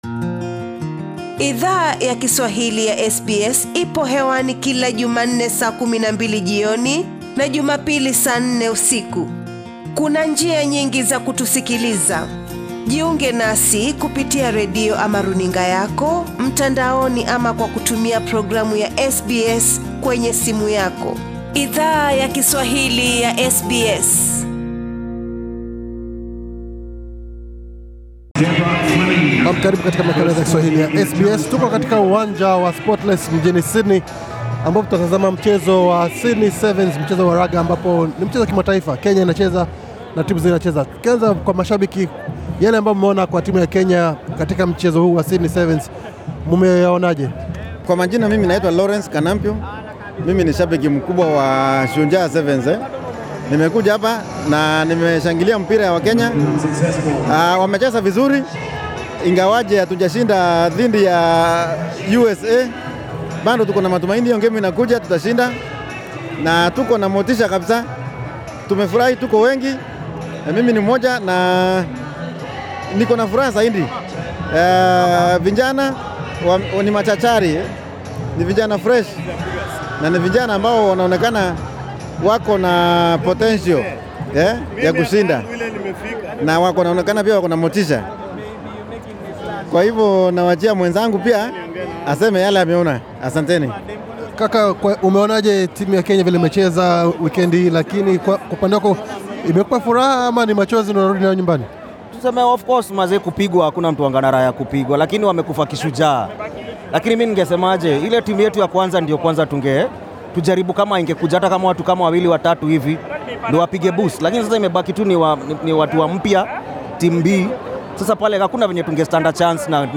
Mashabiki wengi ambao SBS Swahili ilizungumza nao wakati wa michuano hiyo, wali elezea huzuni walio pata, kupitia matokeo ya Shujaa katika michuano hiyo ila, wengi wao wana amini kwamba Shujaa wata rekebisha makosa waliyo fanya nakurejeshea Kenya heshima katika michuano ya raga inayo salia.